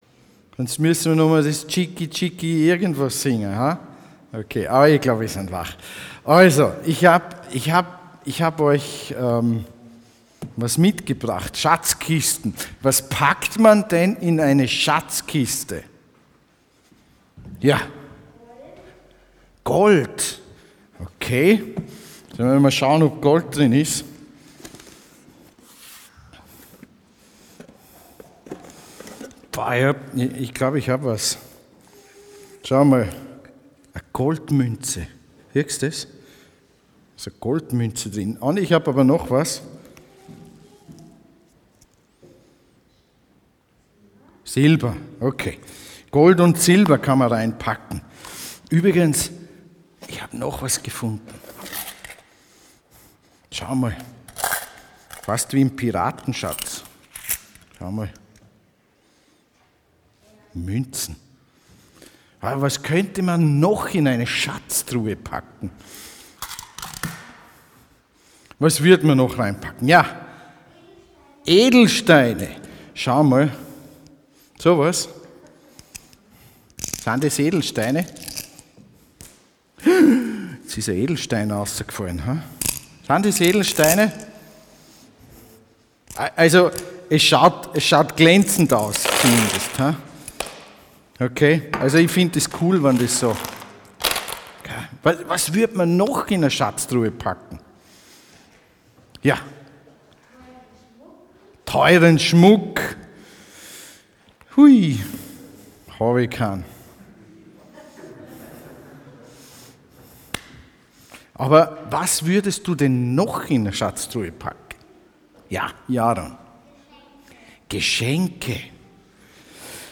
Schätze sammeln (Familiengottesdienst)